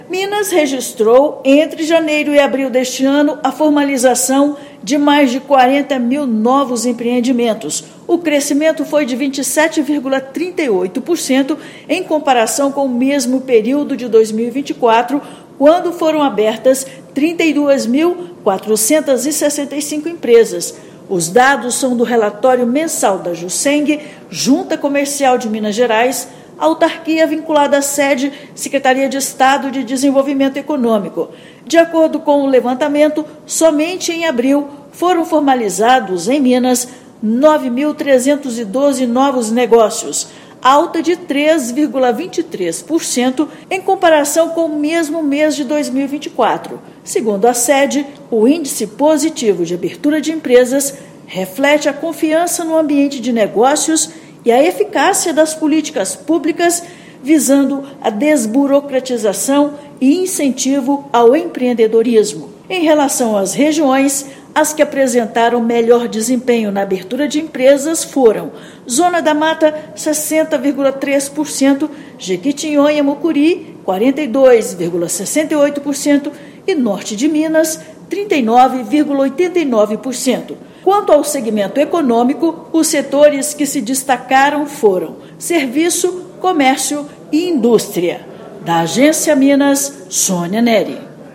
[RÁDIO] Minas Gerais já contabiliza mais de 41 mil novas empresas este ano
Relatório da Jucemg aponta alta de 27,38% em relação ao mesmo período de 2024; Zona da Mata, Jequitinhonha/Mucuri e Norte de Minas são destaque. Ouça matéria de rádio.